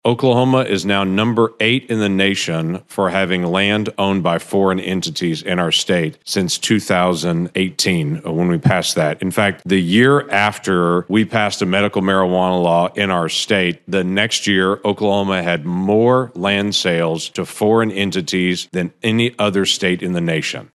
It’s what he indicated as he spoke at the start of a Senate hearing and campaigned for his Security and Oversight of International Landholdings (SOIL) Act.
Sen. Lankford made the statements during hearings by the Senate Agriculture, Nutrition, and Forestry committee and the Senate Select Committee on Intelligence.